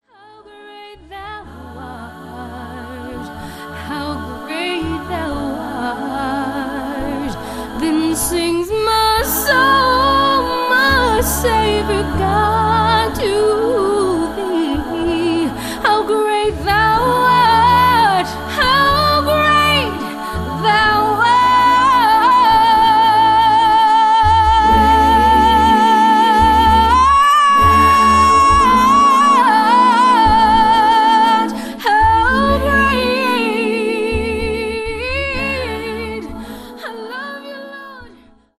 Your source for the best in A'cappella Christian Vusic ®
a cappella gospel songstress